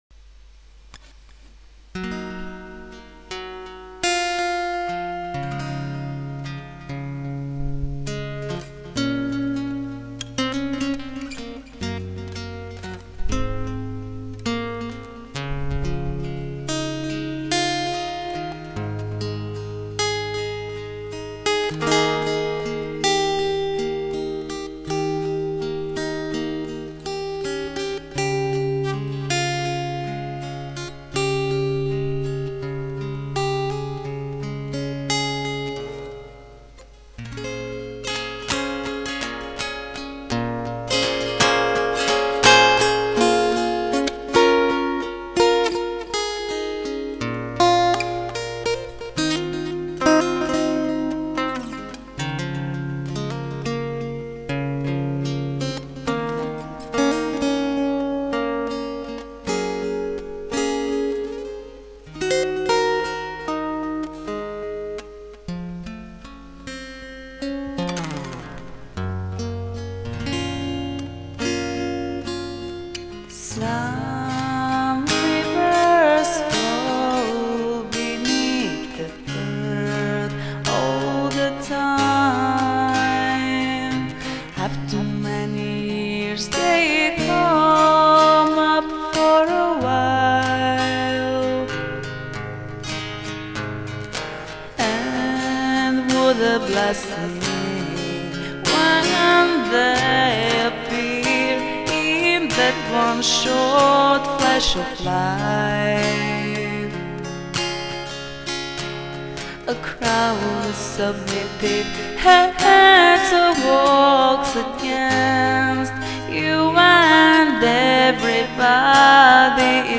Toto jedna demo verzia, ktorej som sa doteraz nevenoval, takže je tam zopár hudobných chybičiek a aj v anglickej výslovnosti je jedna chyba, inak som s tým úplne spokojný. Hudba-gitara a text je moja tvorba